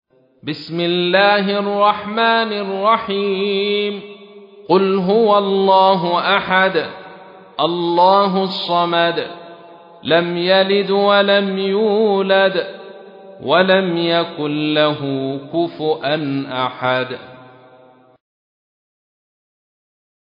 تحميل : 112. سورة الإخلاص / القارئ عبد الرشيد صوفي / القرآن الكريم / موقع يا حسين